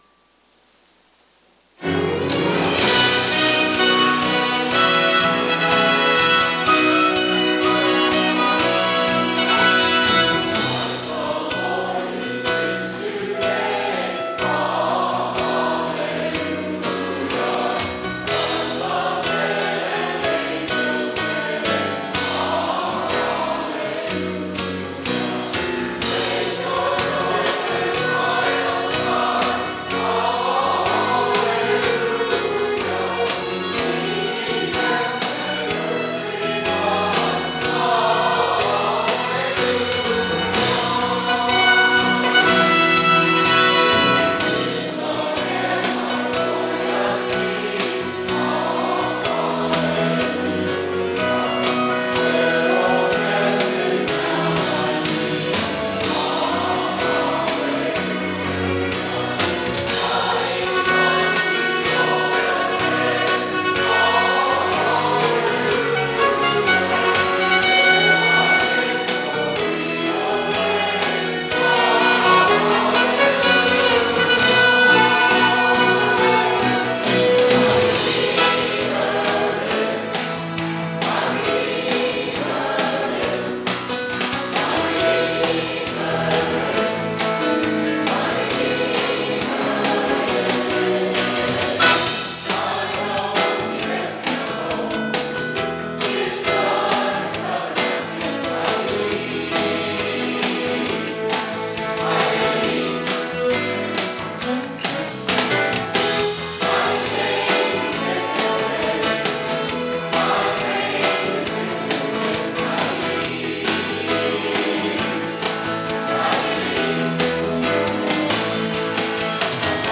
Crimson Love. An Easter Worship Service Musical - Pleasant Grove Baptist Church Ellijay Georgia
Presented by the Pleasant Grove Sanctuary Choir.